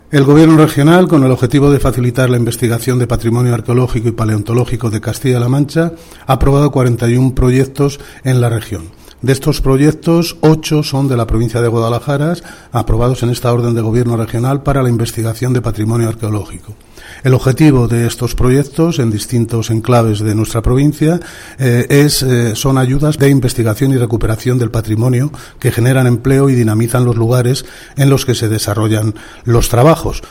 El director provincial de Educación, Cultura y Deportes, Faustino Lozano, habla de la importancia de las ayudas a la investigación arqueológica y paleontológica impulsadas por el Gobierno regional.